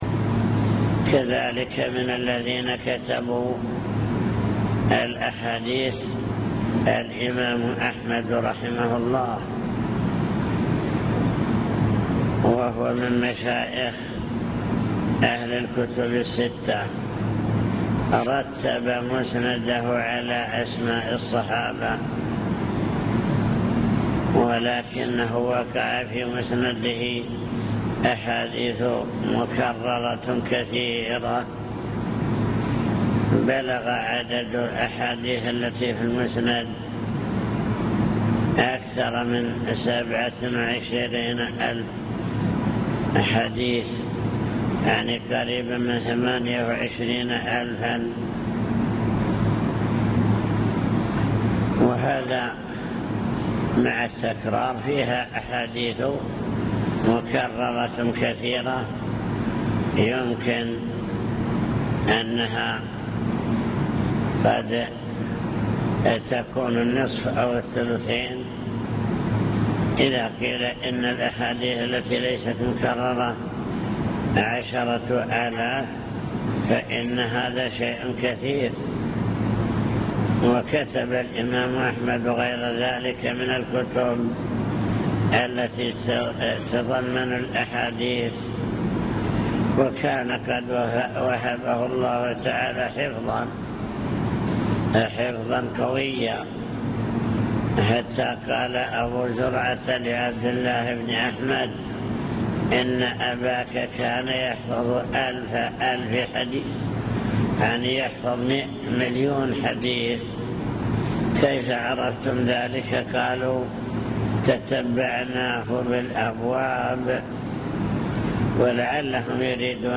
المكتبة الصوتية  تسجيلات - محاضرات ودروس  محاضرات بعنوان: عناية السلف بالحديث الشريف دور أصحاب الكتب الستة في حفظ الحديث